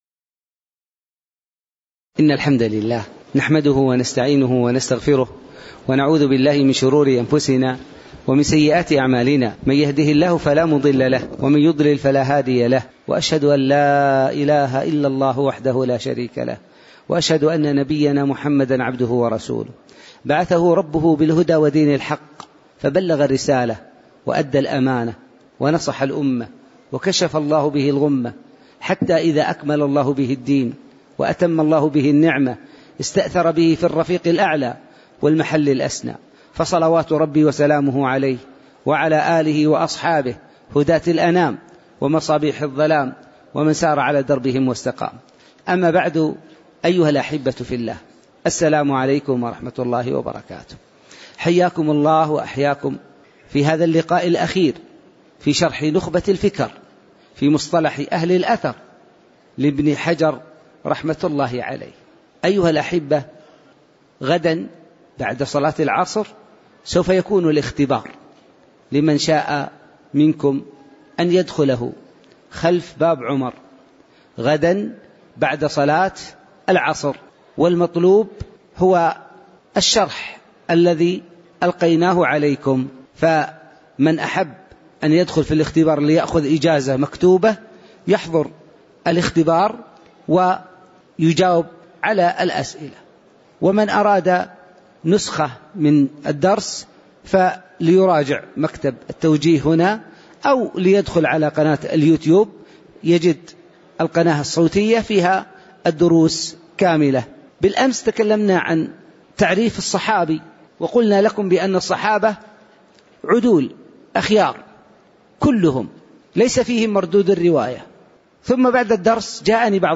تاريخ النشر ١٤ شوال ١٤٣٩ هـ المكان: المسجد النبوي الشيخ